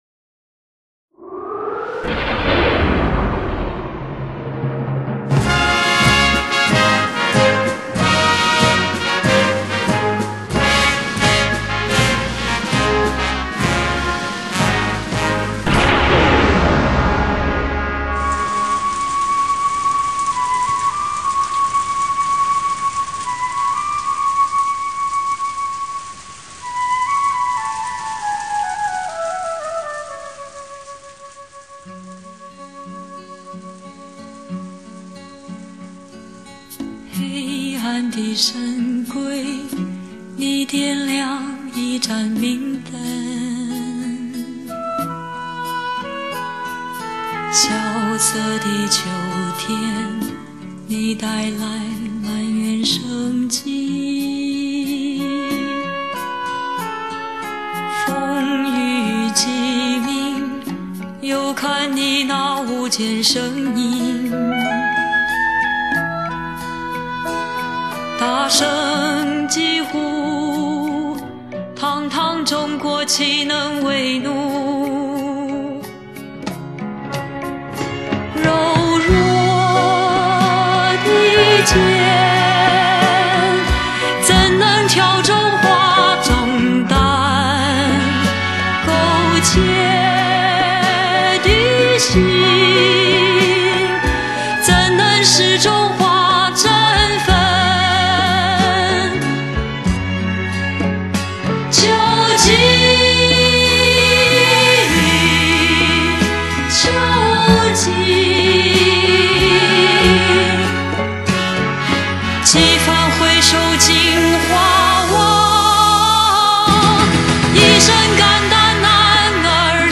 低沉优雅的嗓音，蕴含着成熟女性特有的性感。